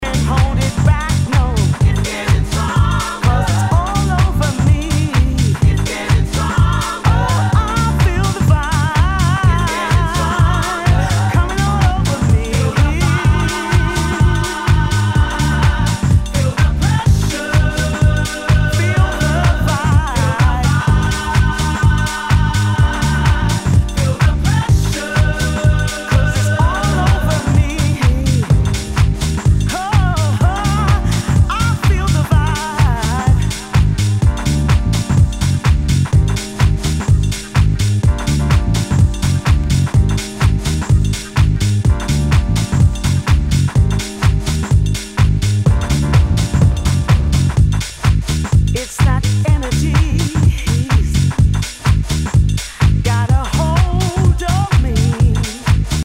HOUSE/TECHNO/ELECTRO
ナイス！ディープ・ヴォーカル・ハウス！